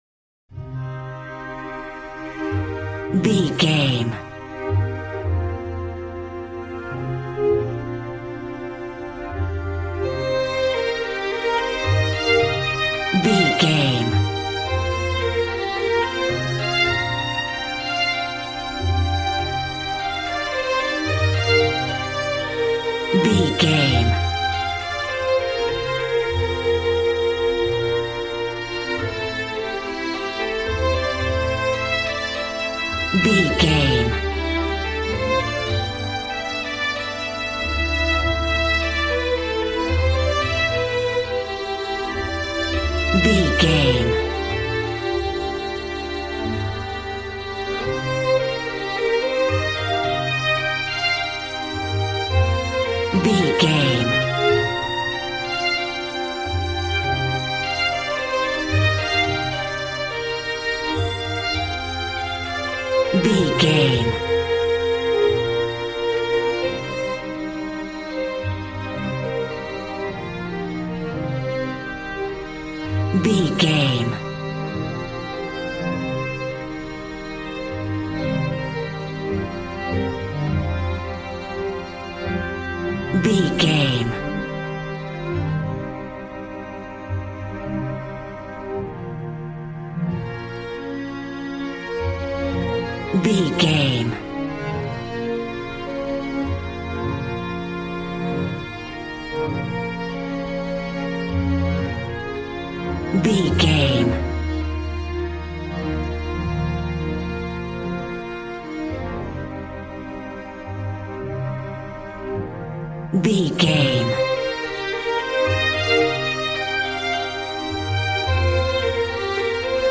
Aeolian/Minor
joyful
conga